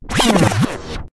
Звуки эдитов, битов
Перемотка времени